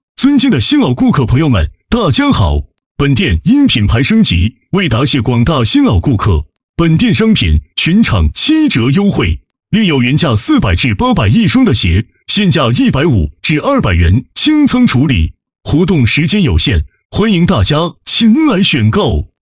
男声配音员 更多+
• 男-061号